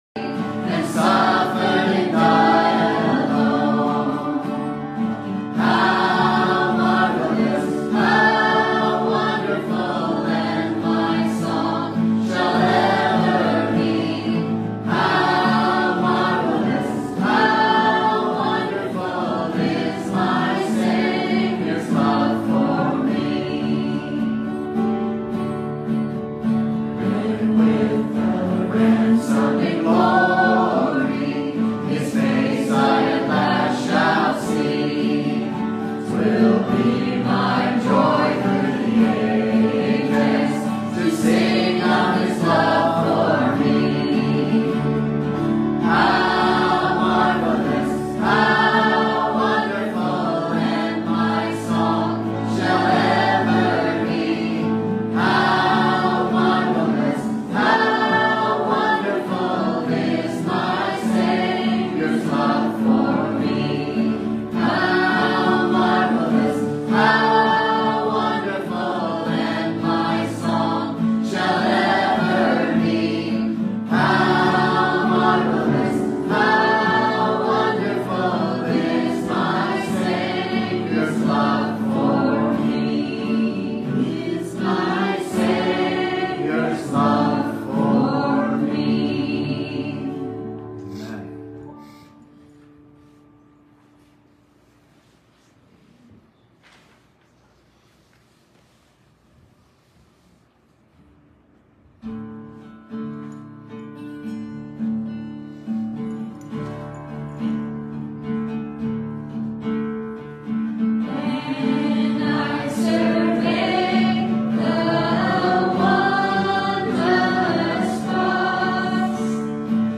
Romans Passage: Romans 10:14-21 Service Type: Sunday Morning Topics